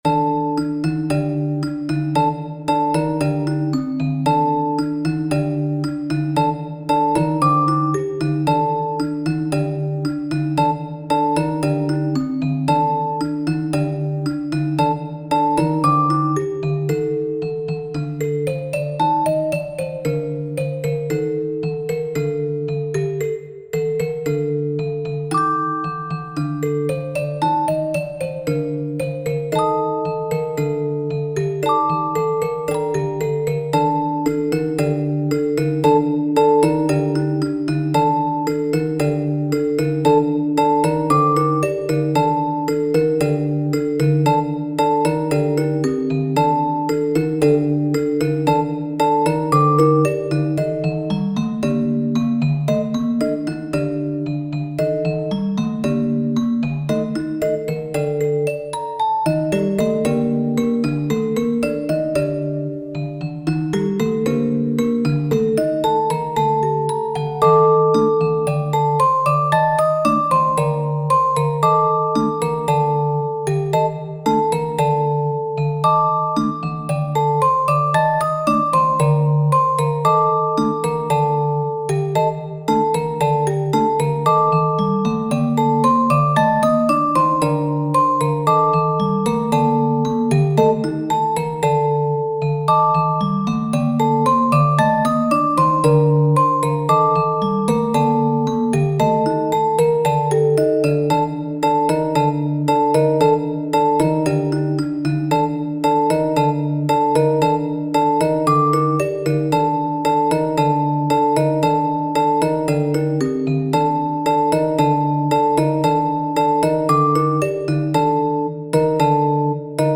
カミシバイをテーマにした和風ホラーオルゴール楽曲。
4度のボイシングを特徴的なリズムでリフレインすることでカミカクシっぽい神秘的な畏れ感を出そうとしている。